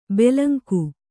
♪ belanku